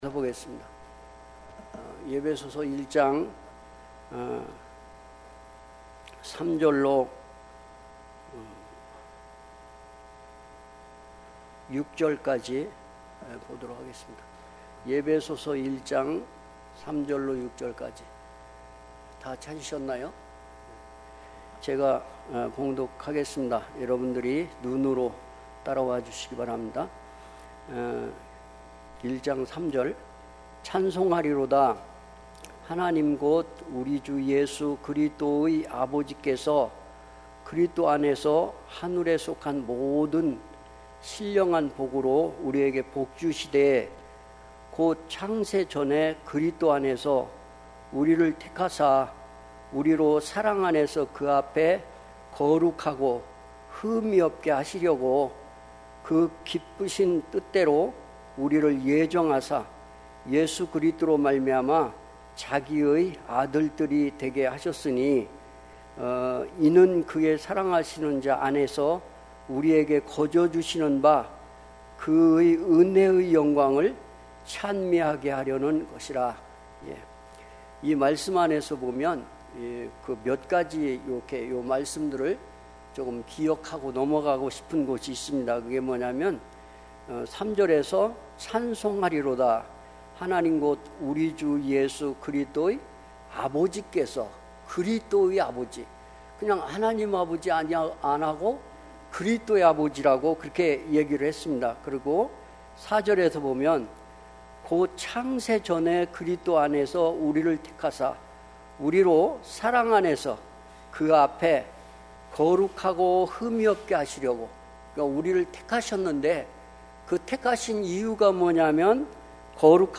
특별집회 - 에베소서 1장 3-6절